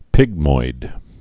(pĭgmoid)